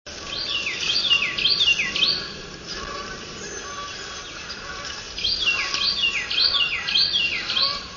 Carolina Wren
wren_carolina_704.wav